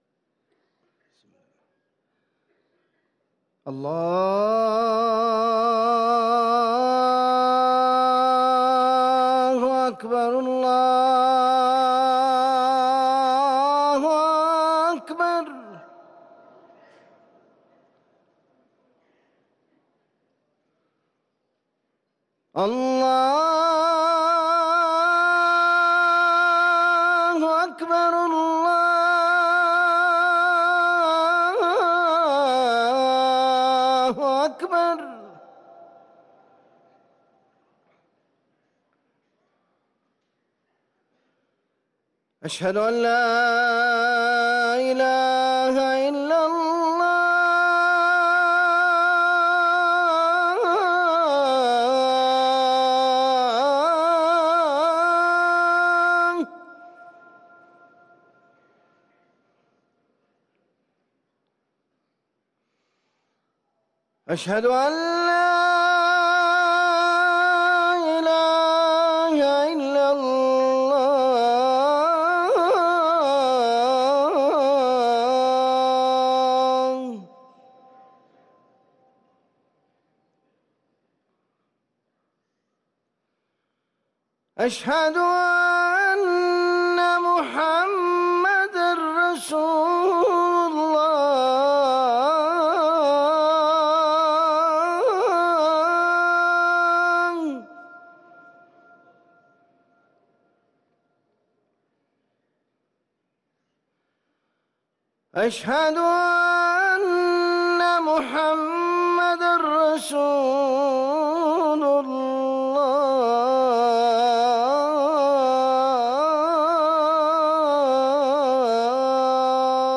أذان المغرب